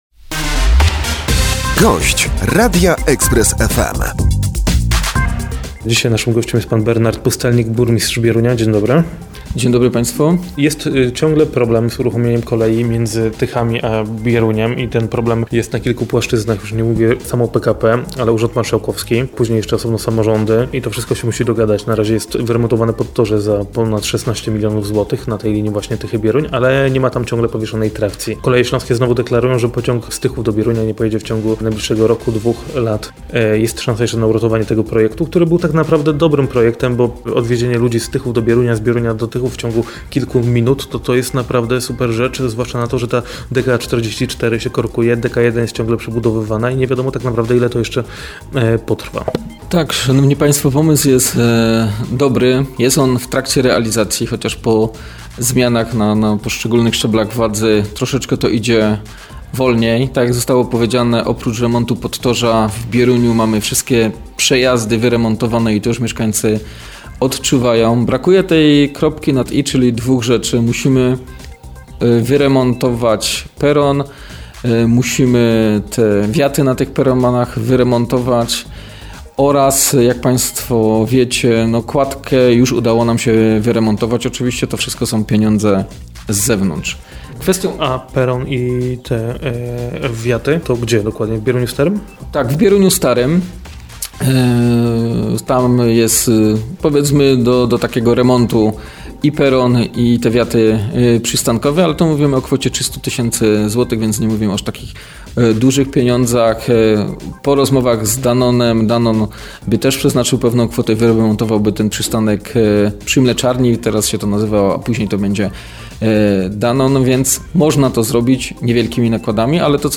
14.04.2014 - Rozmowa z burmistrzem Bierunia Bernardem Pustelnikiem. O połączeniu kolejowym Tychy-Bieruń.